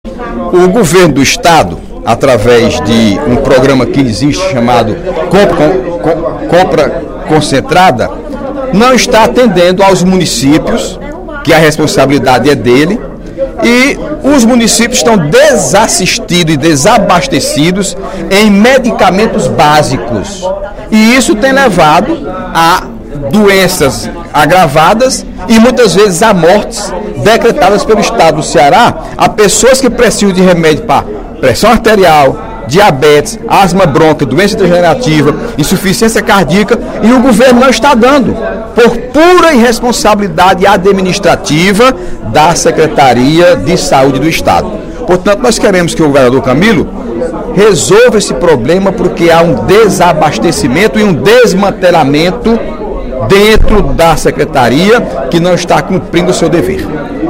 O deputado Heitor Férrer (PDT) anunciou, durante o primeiro expediente da sessão plenária da Assembleia Legislativa desta quarta-feira (24/06), que vai pedir, por requerimento, explicações de como estão sendo processadas as compras de medicamentos pela Coordenadoria de Abastecimento Farmacêutico (Coasf) da Secretaria da Saúde do Estado.